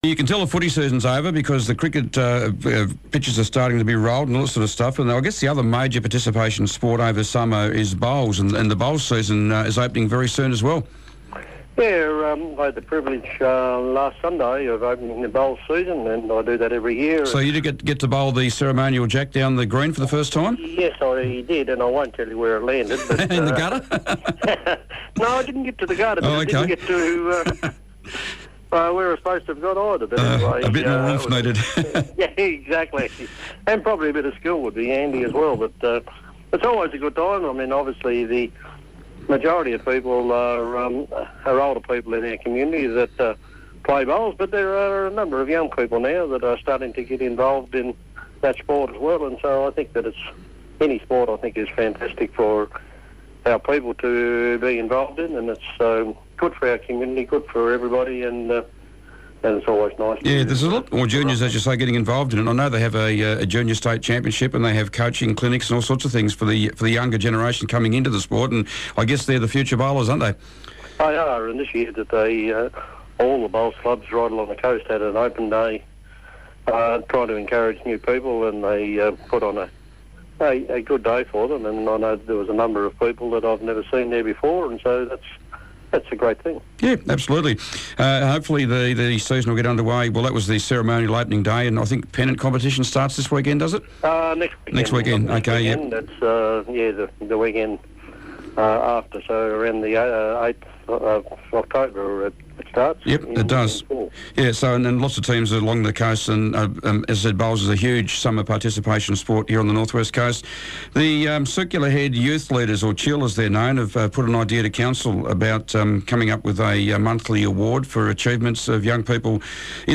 Circular Head Mayor Darryl Quilliam was today's Mayor on the Air. Darryl spoke about the opening of the bowls season, a new youth award and new murals in Smithton . . .